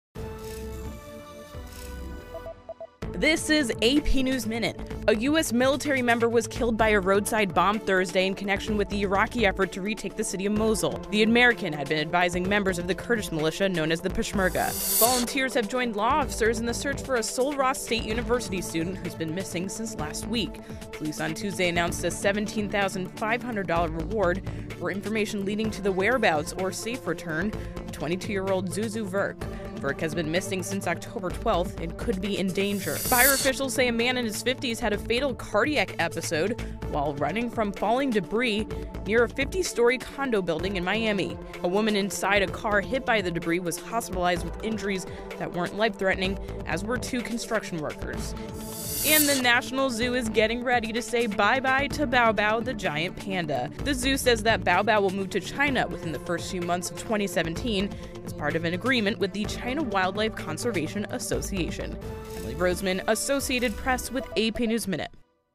英语资讯
News